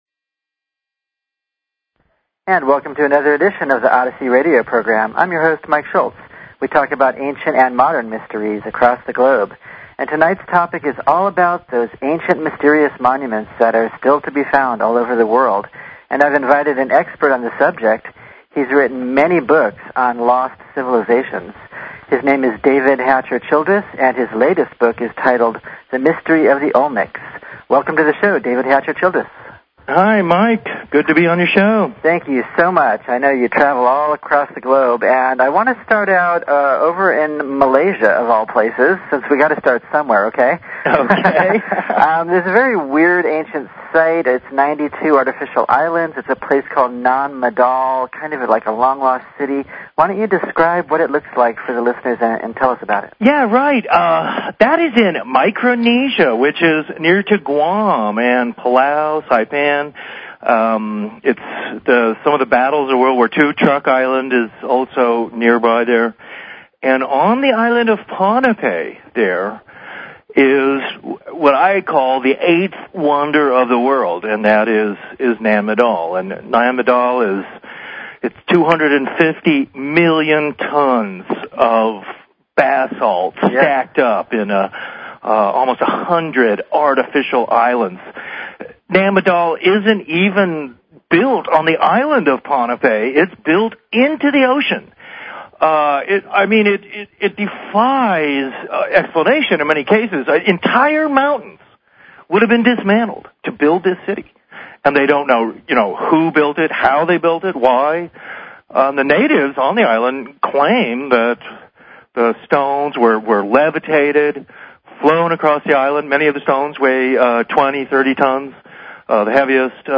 Talk Show Episode, Audio Podcast, Odyssey and Courtesy of BBS Radio on , show guests , about , categorized as
Show Headline Odyssey Show Sub Headline Courtesy of BBS Radio Discussion about ancient monuments and lost cities across the globe with author David Hatcher Childress.